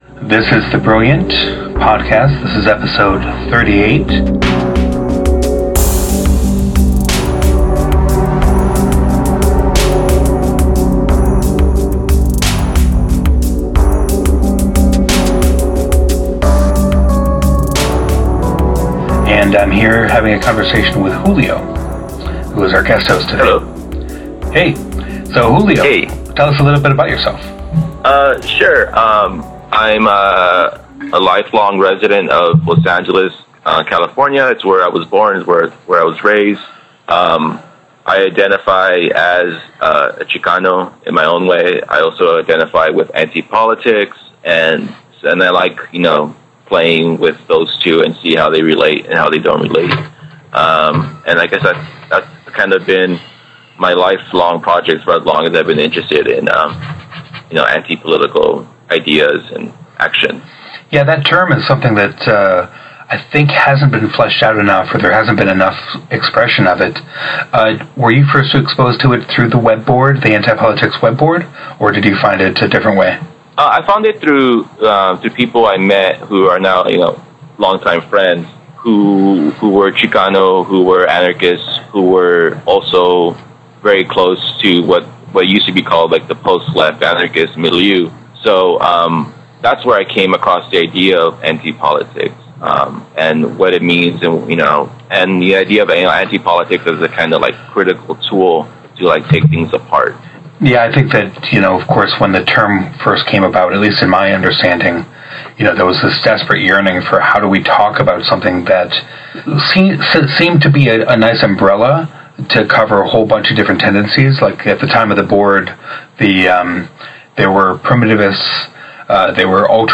While a mechanical keyboard is great, it sounds loud next to a microphone.